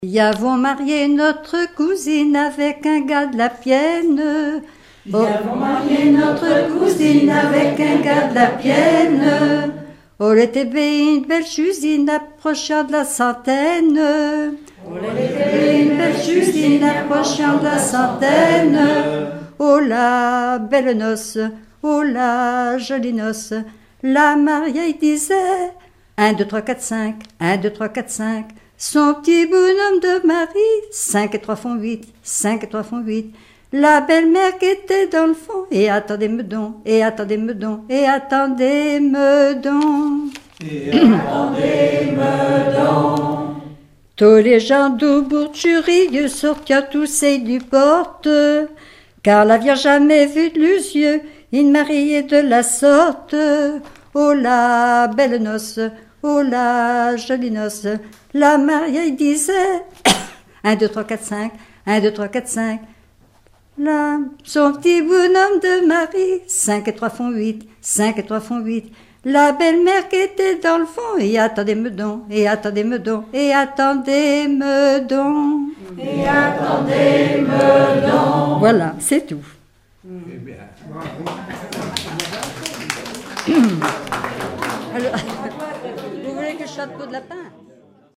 collectif de chanteurs du canton
Pièce musicale inédite